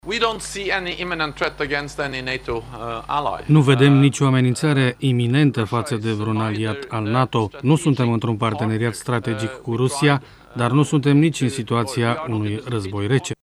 La rândul său, secretarul general al Alianţei, Jens Stoltenberg a declarat că cele 28 de state membre ale NATO sunt „unite” şi deschise dialogului. Cât despre o eventuală ameninţare la care ar fi supuşi aliaţii, Stoltenberg a spus: